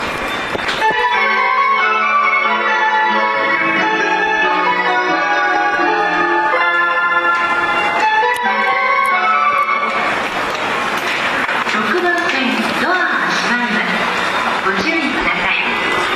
日本の玄関口ですが、メロディーは特に変わっているものでもありません。
発車4音色a --